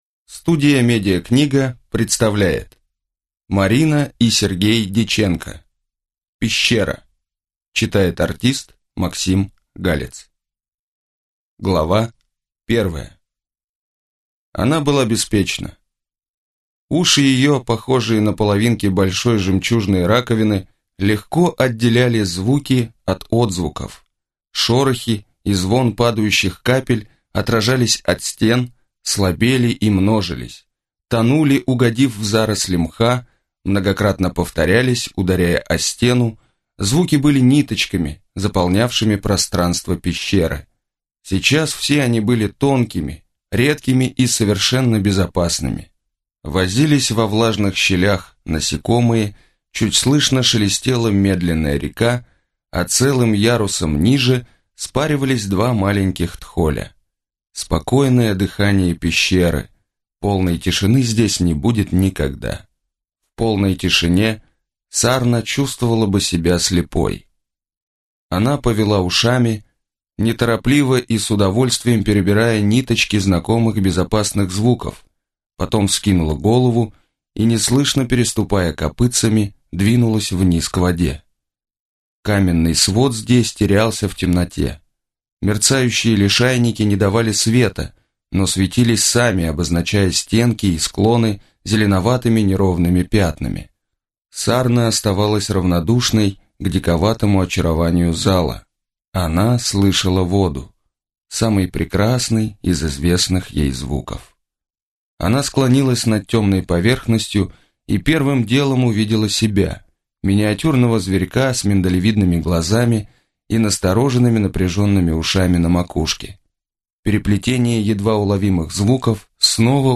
Аудиокнига Пещера | Библиотека аудиокниг